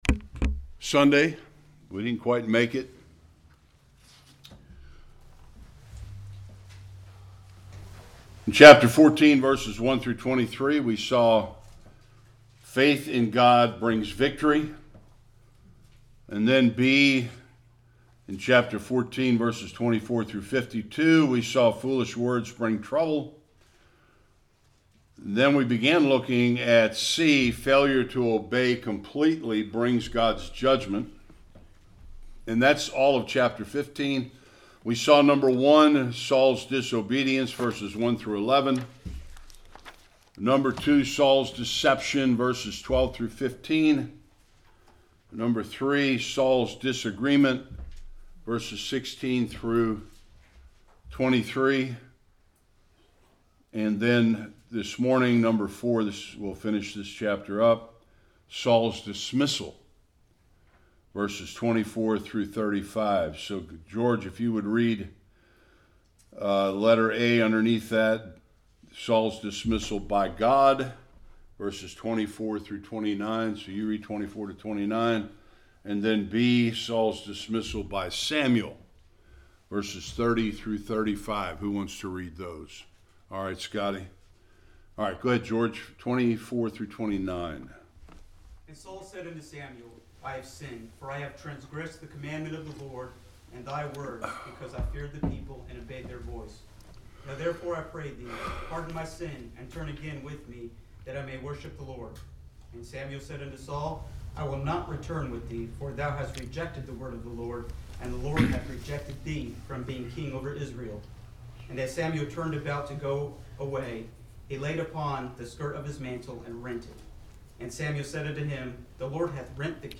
1-5 Service Type: Sunday School King Saul is rejected by the LORD and by Samuel as king.